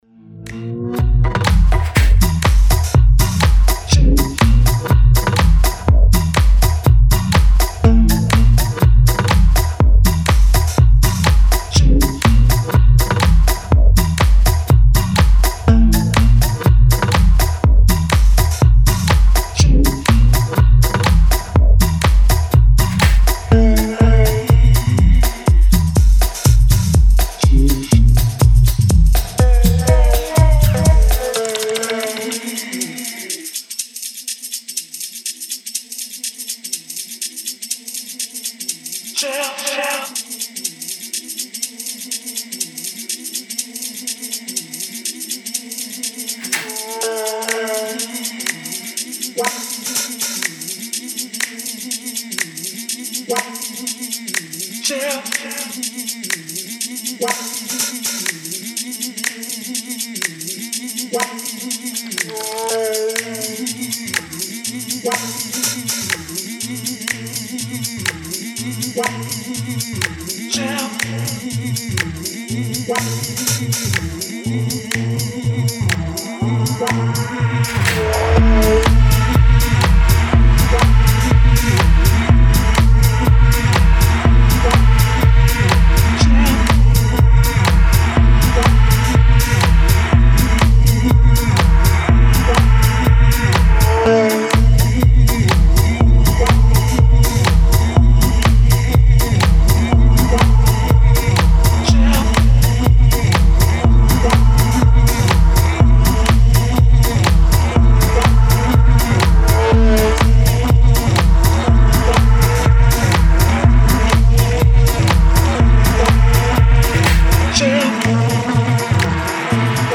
Style: Tech House / Deep House